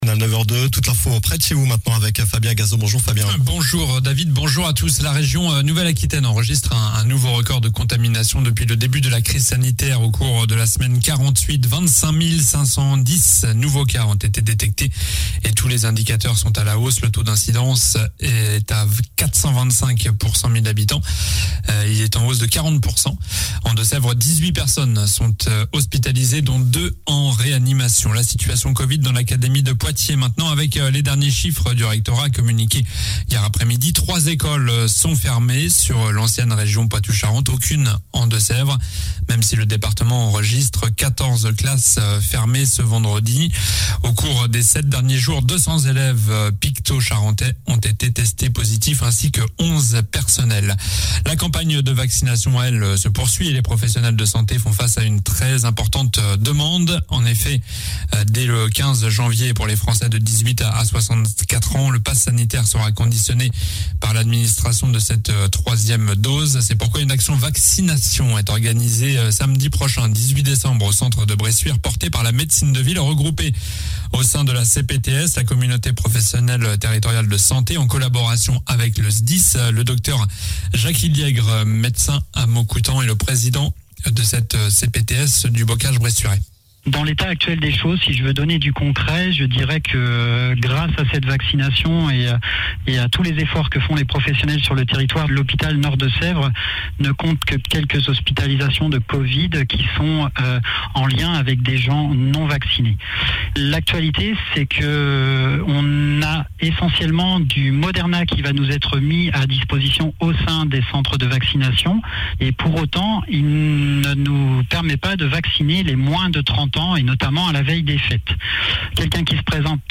Journal du samedi 11 décembre (matin)